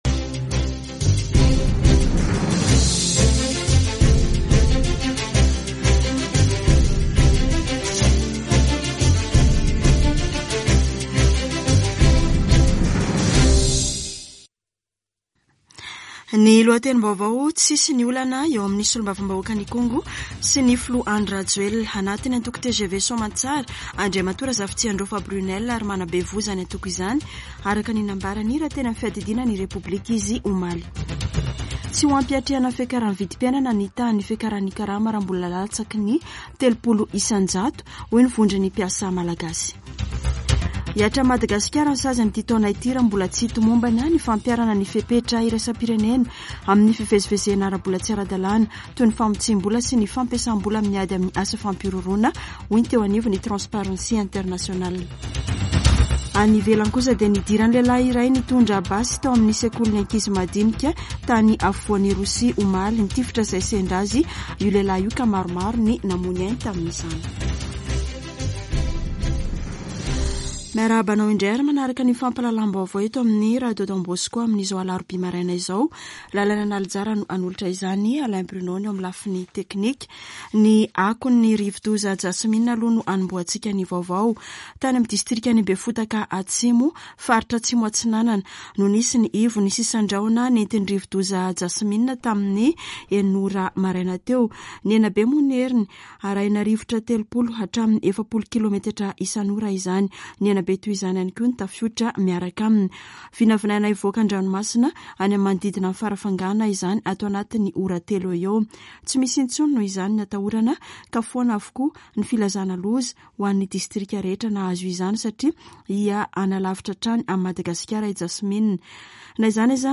[Vaovao maraina] Alarobia 27 avrily 2022